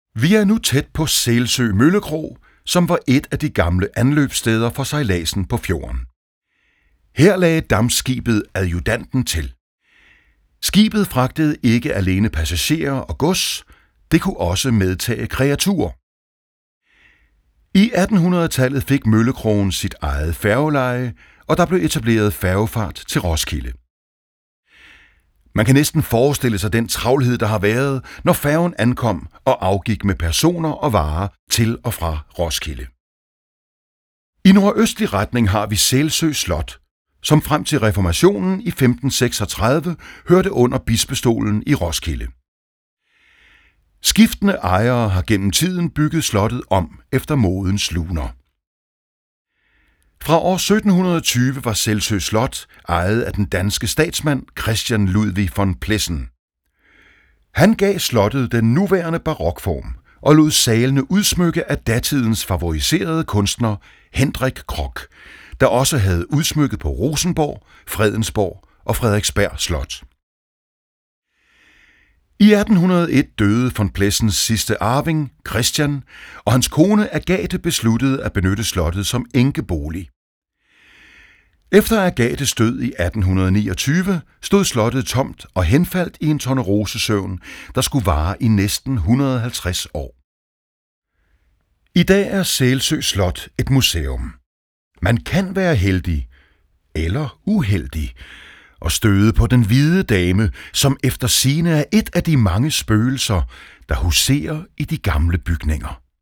SELSØ MASTER SPEAK AF STIG ROSSEN